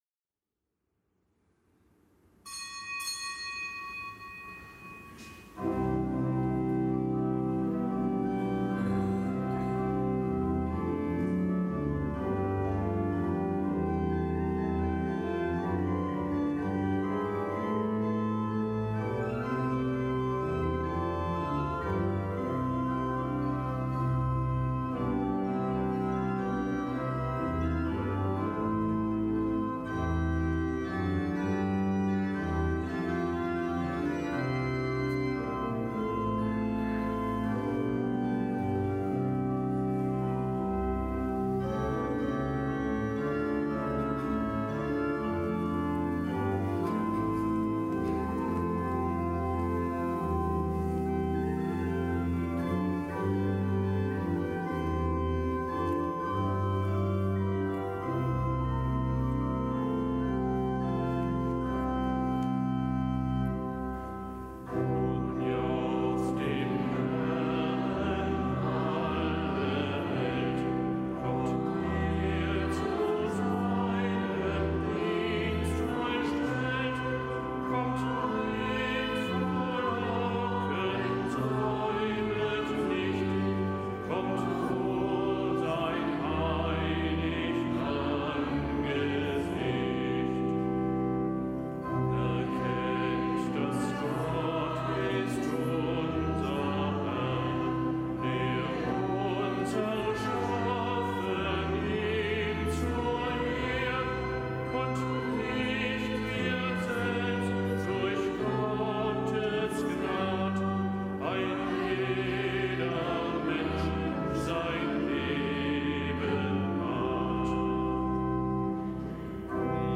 Kapitelsmesse am Dienstag der 31. Woche im Jahreskreis